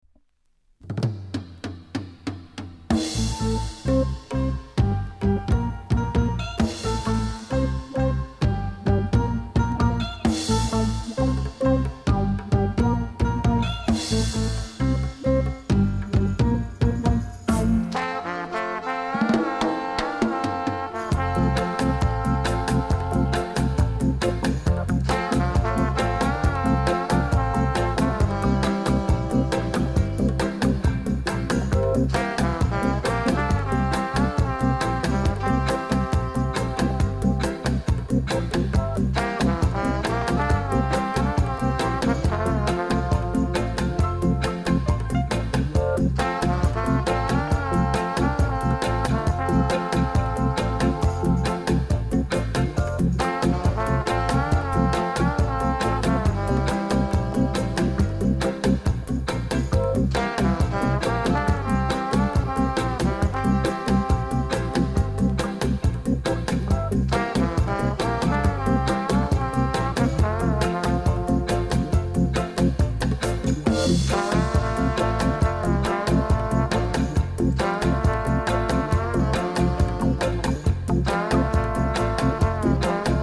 DEEP INST!!